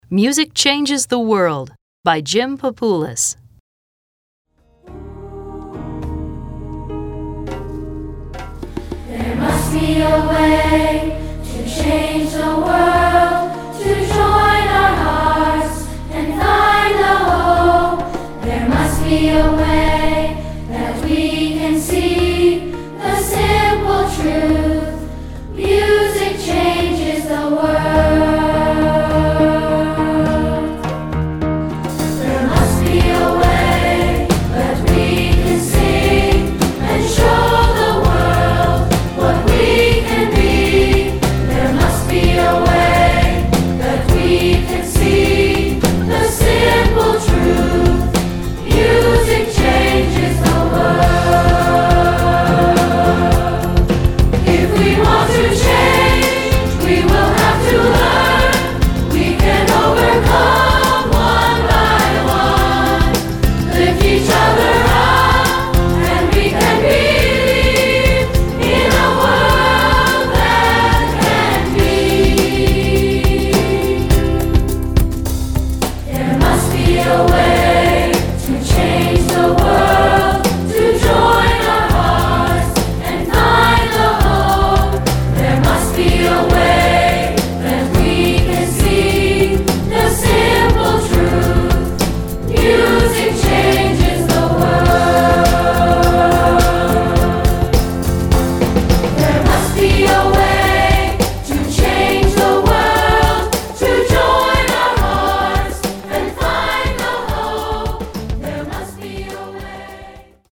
Choral Concert/General Graduation/Inspirational
SATB